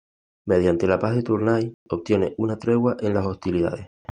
Pronounced as (IPA) /ˈtɾeɡwa/